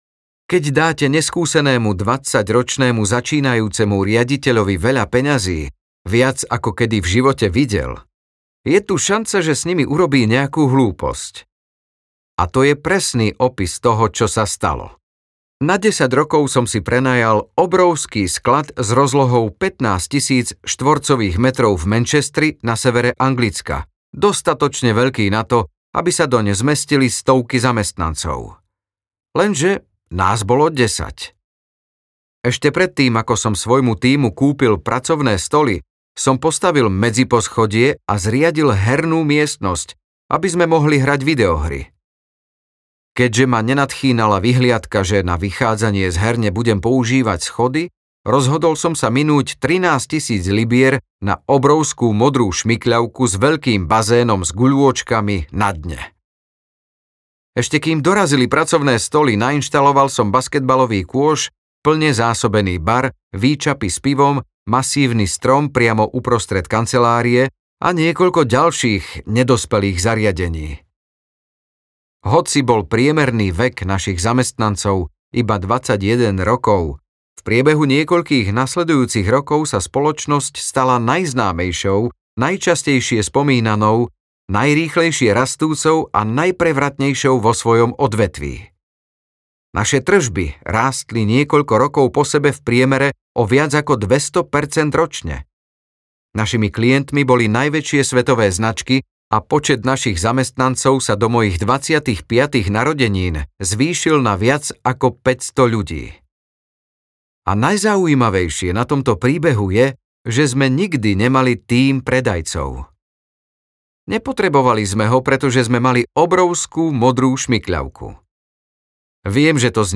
The Diary of a CEO audiokniha
Ukázka z knihy